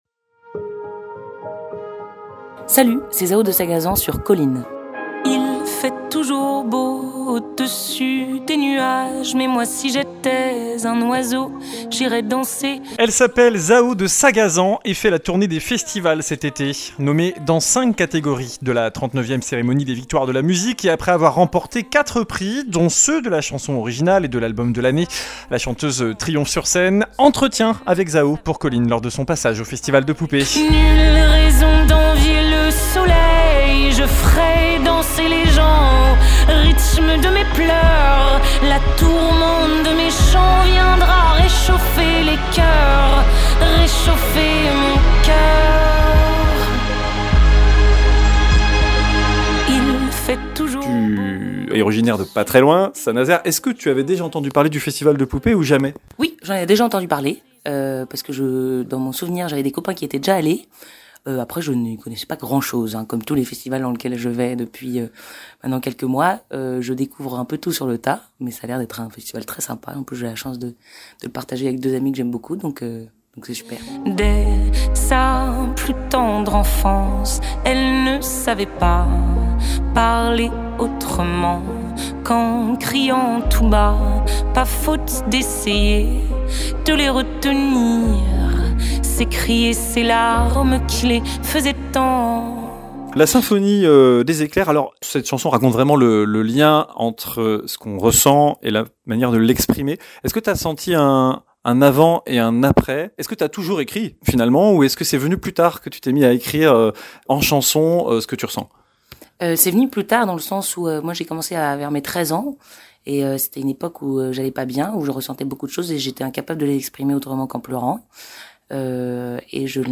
Interview musique Nommée dans cinq catégories de la 39 e cérémonie des Victoires de la musique et après avoir remporté 4 prix, dont ceux de la chanson originale et de l’album de l’année, Zaho de Sagazan triomphe sur scène.